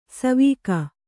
♪ savīka